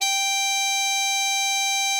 bari_sax_079.wav